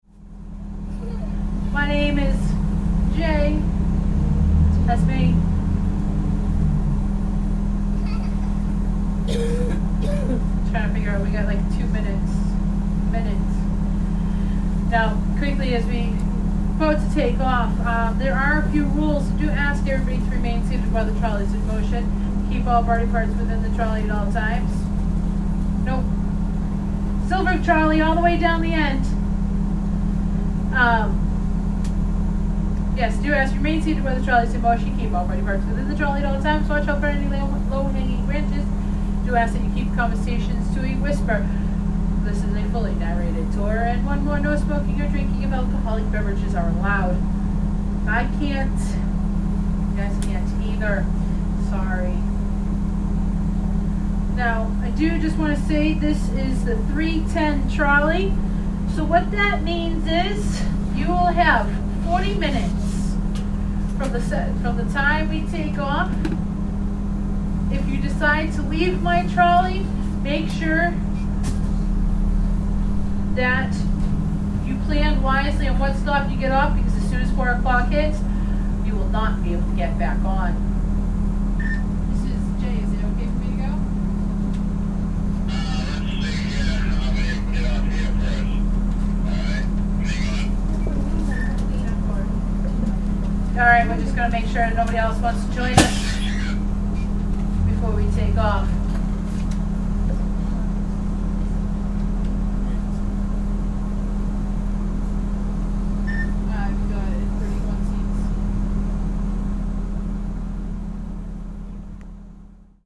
今のところほぼ生音。
アメリカのバス の中               女性ガイドさん の声 01.52